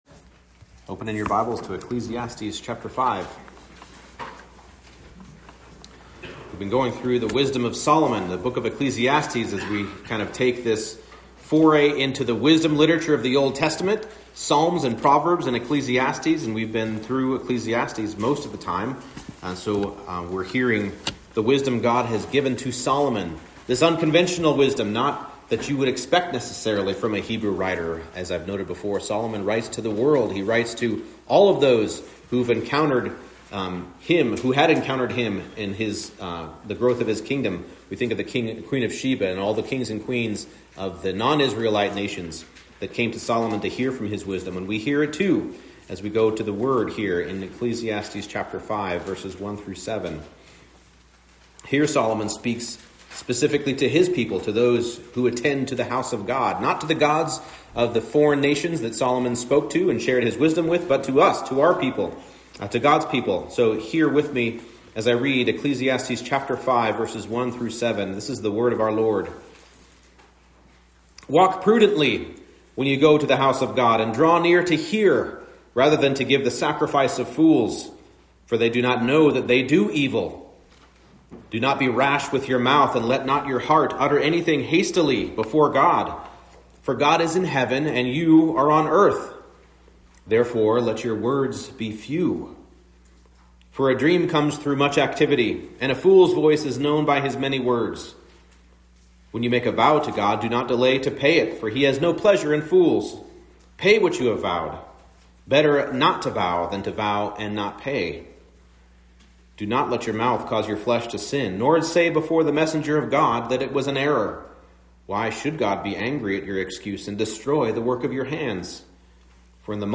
Ecclesiastes 5:1-7 Service Type: Morning Service The wise worshiper approaches God with reverence and awe.